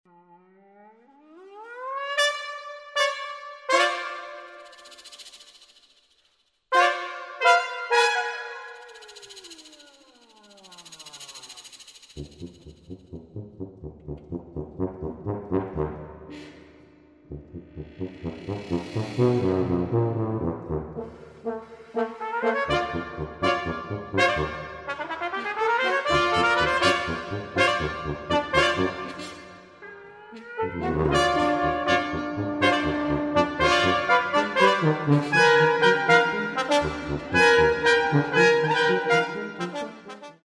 Quintetto di Ottoni
Brass quintet